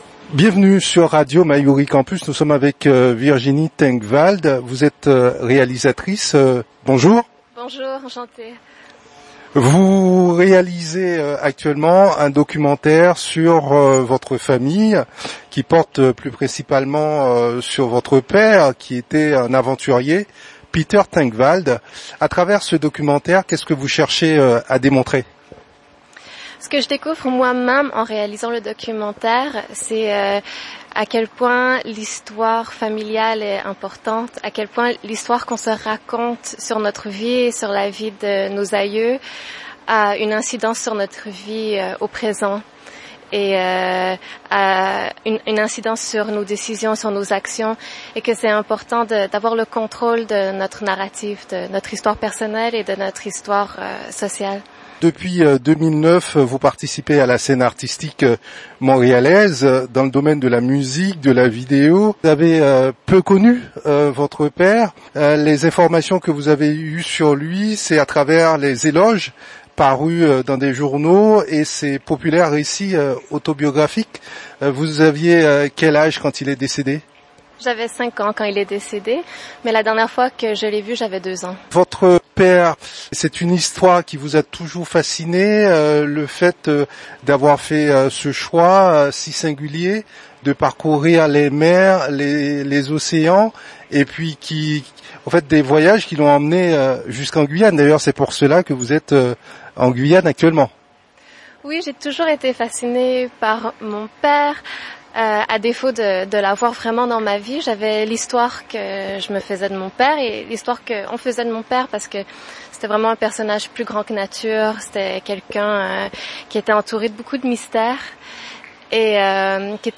La jeune femme consacre un documentaire à cette histoire. Nous l'avons rencontrée à Cayenne, elle répond à nos questions.